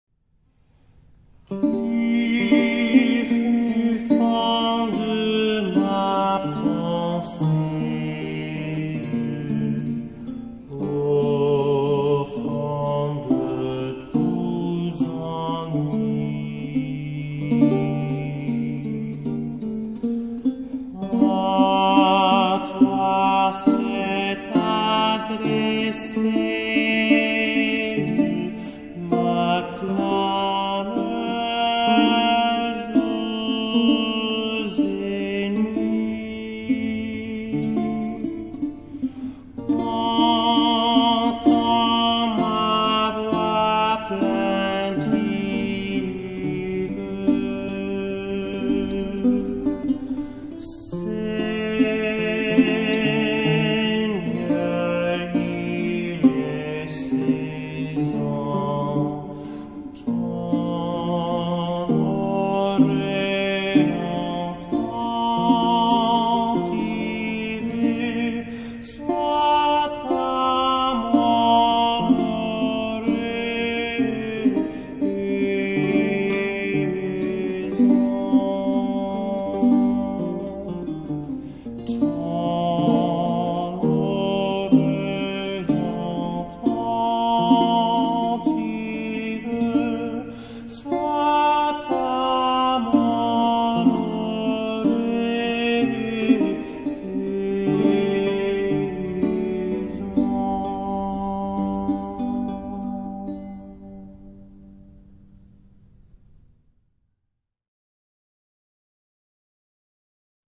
chamber music
classical
vihuela, renaissance and baroque lute